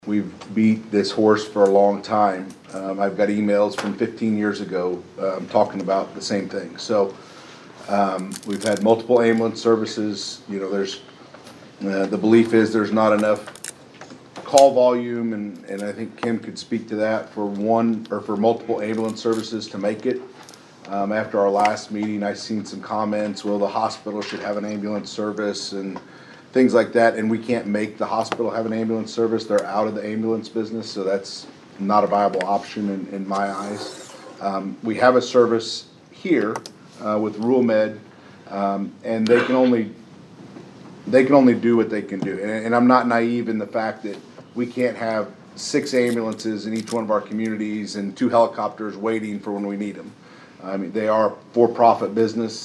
A large group was on hand on Thursday evening at Vandalia City Hall, looking to figure out the best way to secure an ambulance contract for Fayette County.
Vandalia Mayor Rick Gottman kicked off the meeting and then gave the floor to Vandalia Police Chief Jeff Ray, who says this has been a topic of discussion for a long, long time.